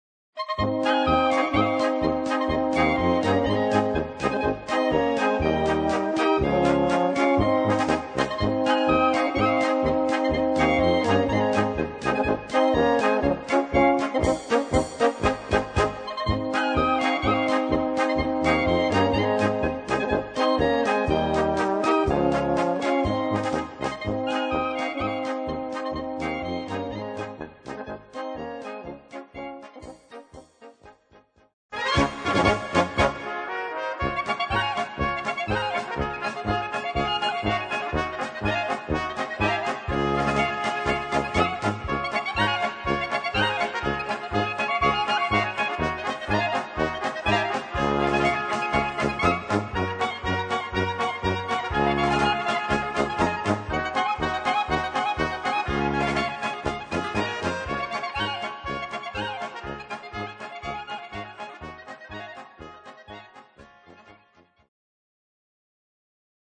Gattung: Polka für Soloklarinette Es und B
Besetzung: Blasorchester